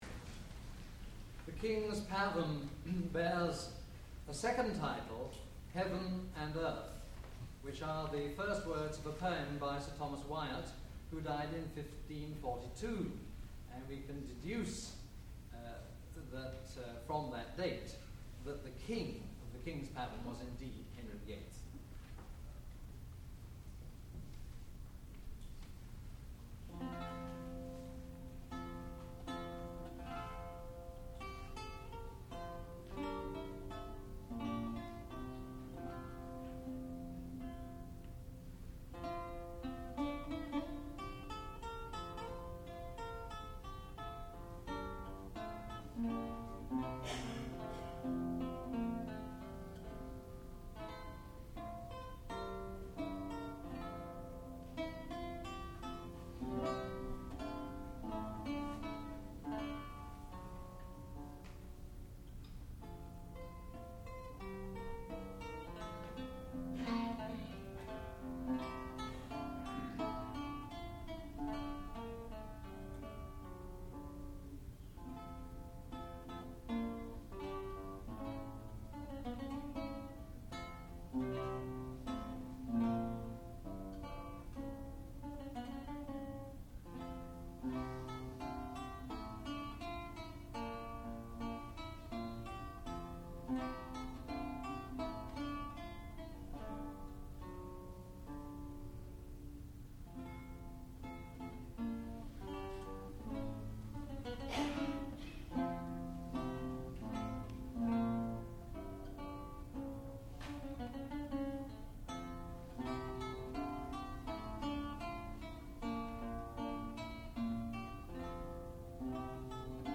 sound recording-musical
classical music